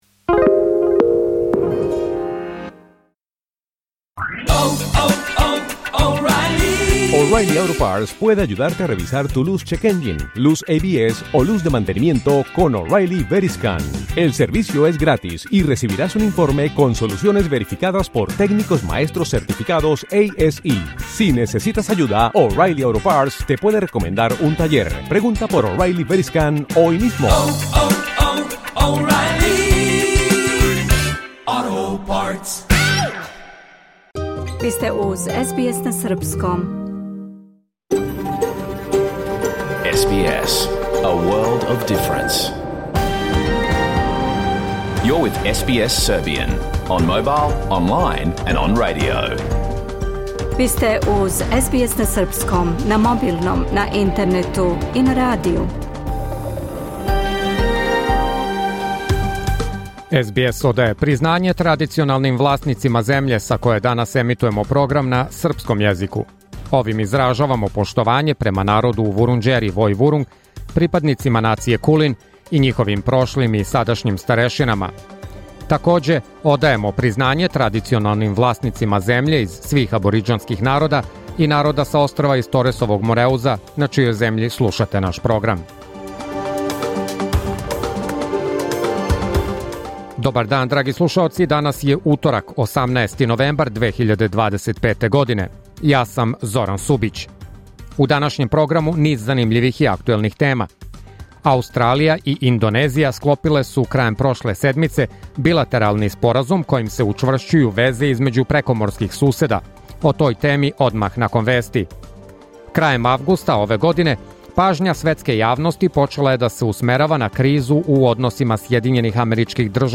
Програм емитован уживо 18. новембра 2025. године